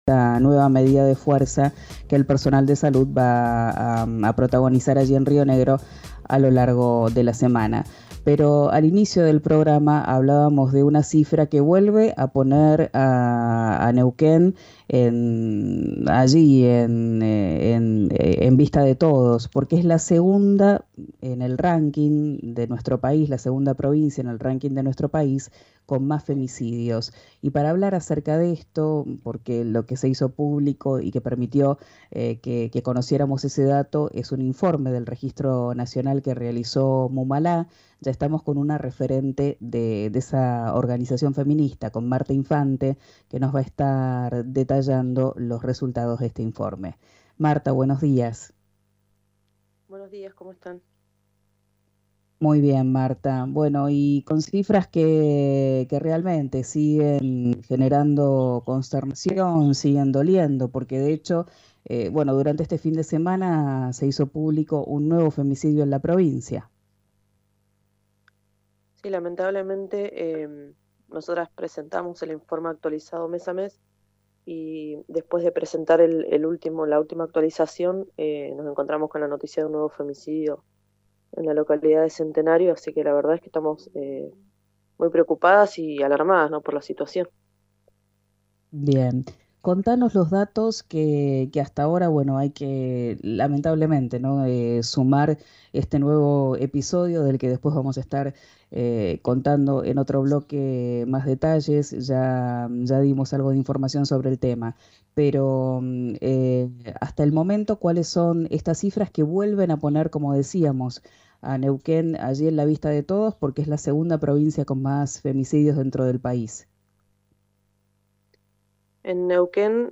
dialogó con RÍO NEGRO RADIO y contó los detalles del informe.